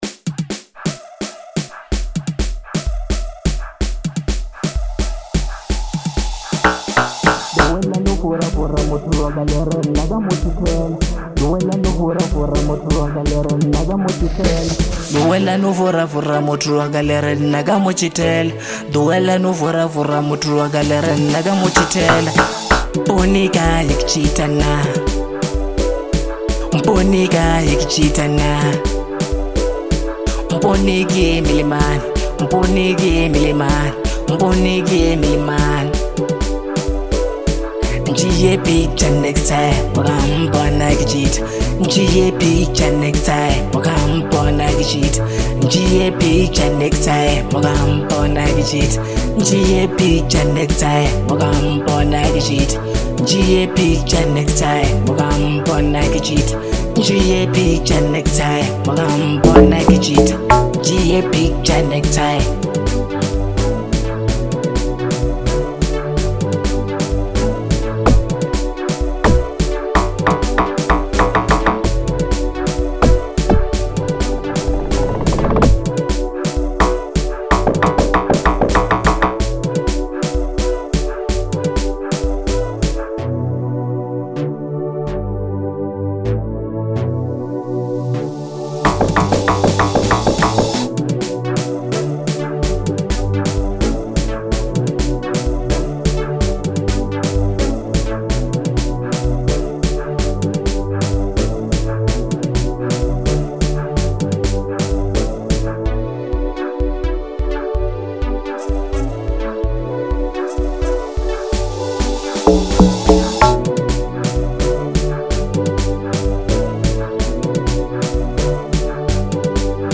04:00 Genre : House Size